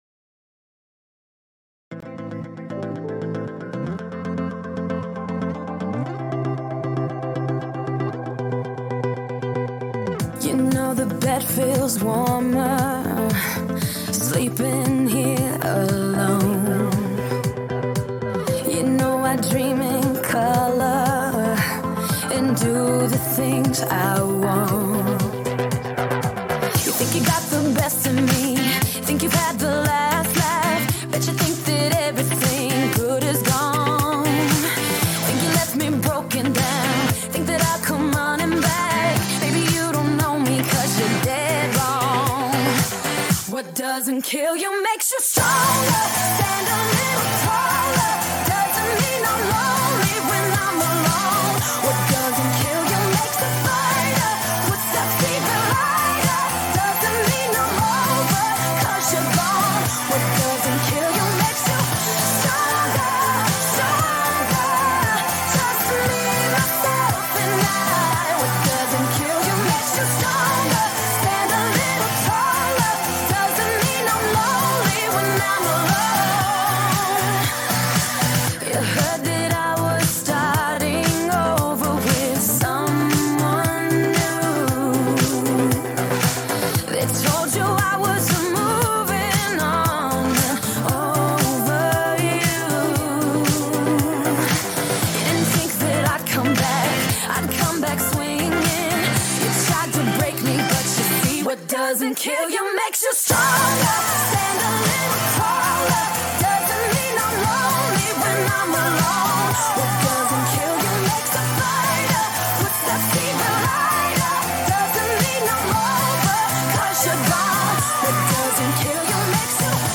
SB live